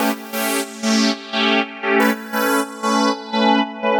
GnS_Pad-MiscB1:4_120-A.wav